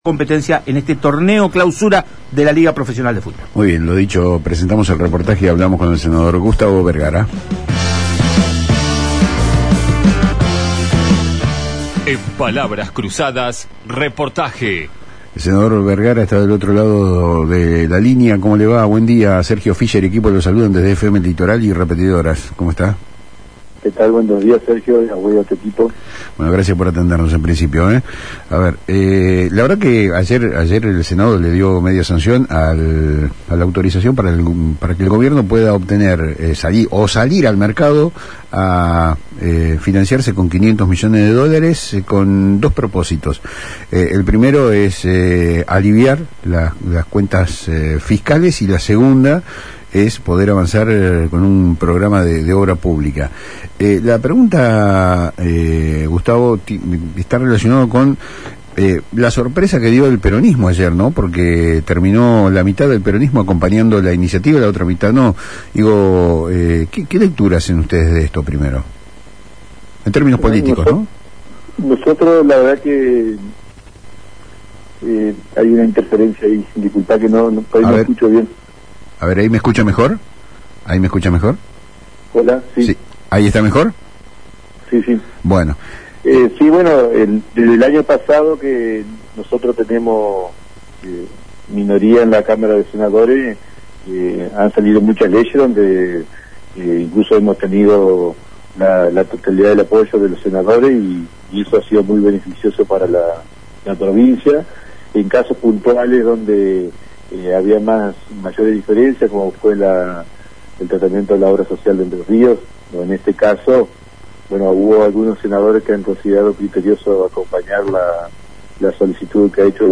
La iniciativa tiene un doble propósito, según el senador Gustavo Vergara, quien dialogó con el programa «Palabras Cruzadas» por FM Litoral: reestructurar la deuda existente y liberar fondos para la obra pública.